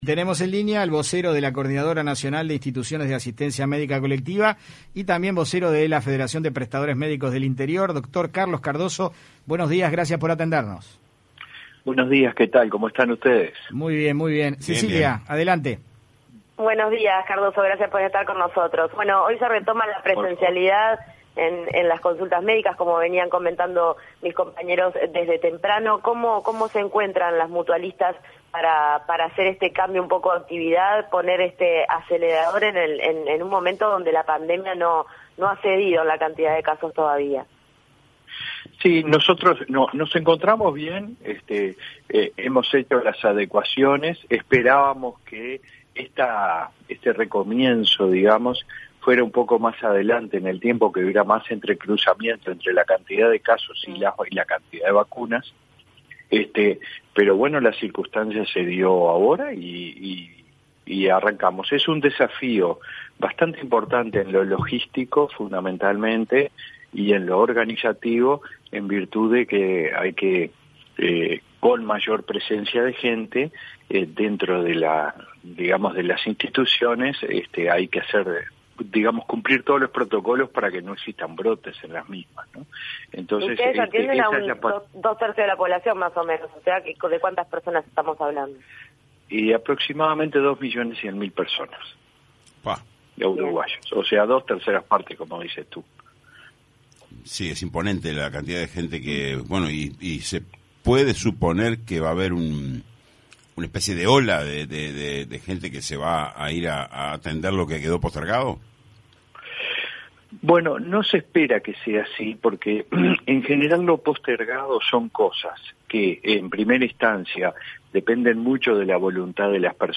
En entrevista con 970 Noticias Primera Edición